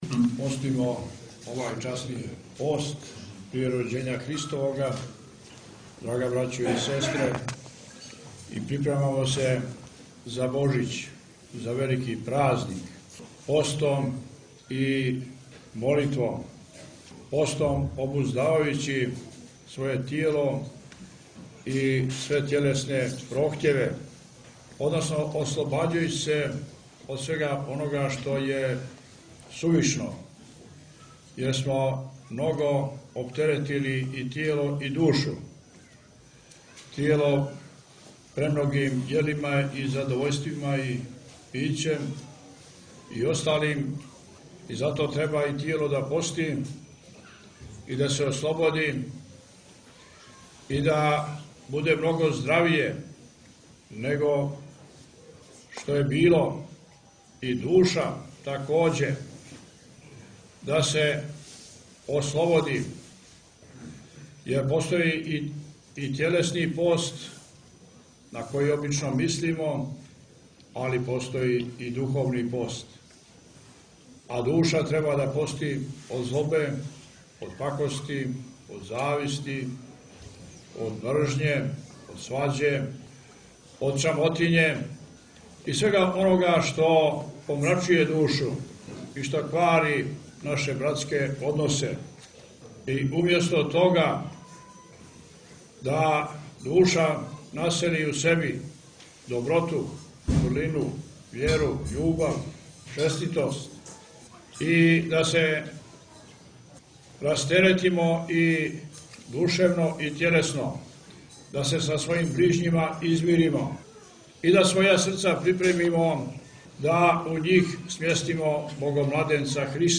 Митрополит Јоаникије у цркви под Горицом
Име: 22.12.2024-mitropolit Joanikije-Sveti Djordje-besjeda.; Опис: Митрополит Јоаникије у цркви под Горицом Тип: audio/mpeg